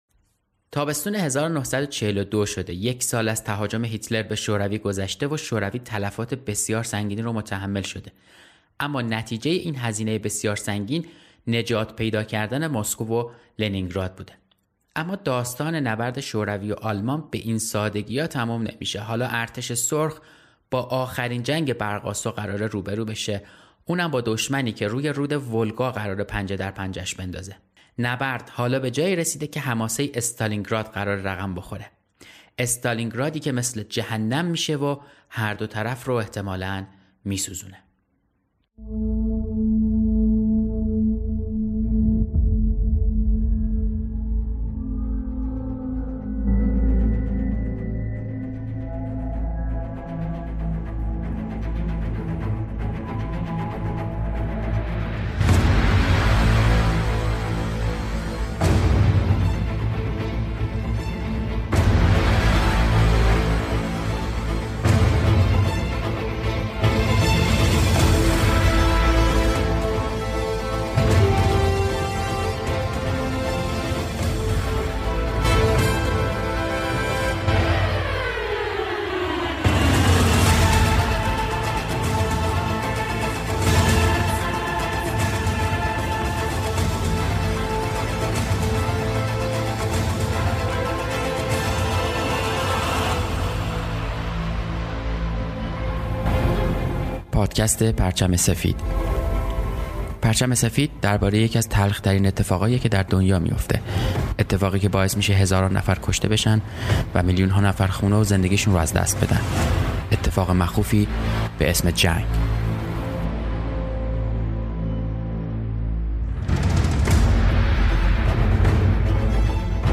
صداهایی که در پادکست می‌شنوید هم از یوتیوب و ویدئوهای مستند استفاده شده است.